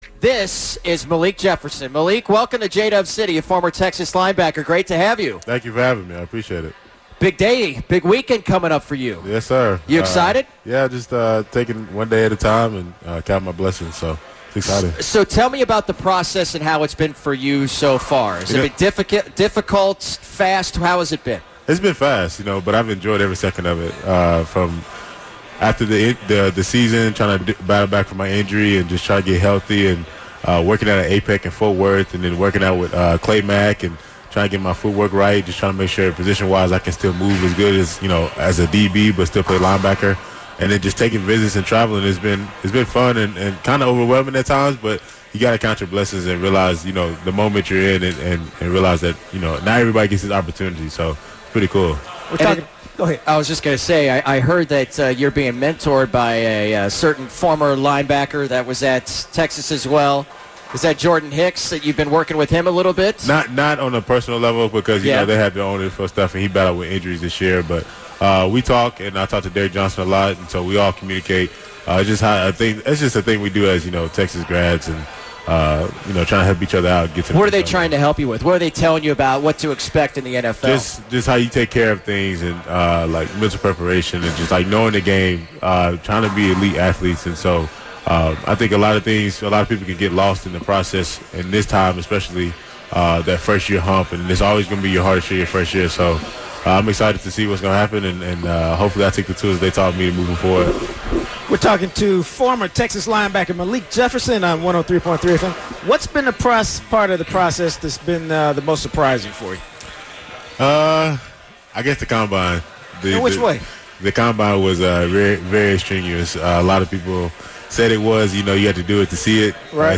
from the ESPN Experience at Pinstack in Plano to discuss his time at Texas, the NFL Draft and more on 103.3 FM ESPN.